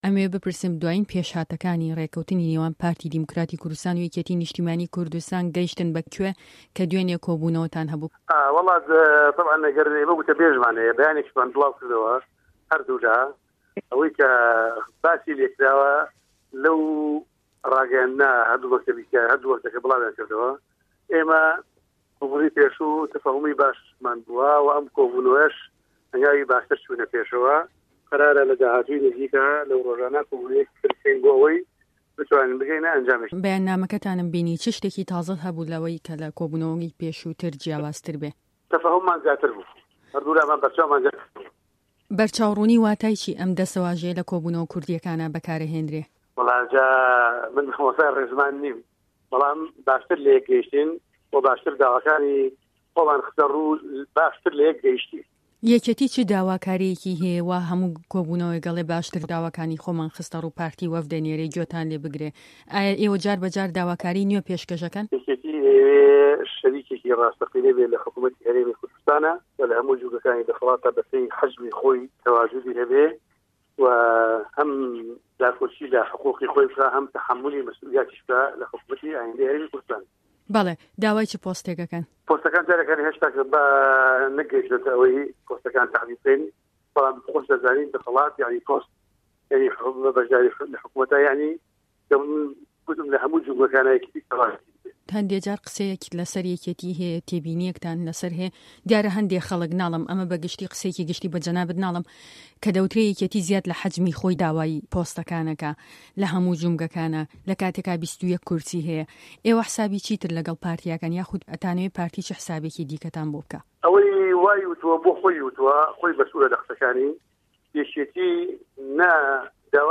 وتووێژه‌كه‌ی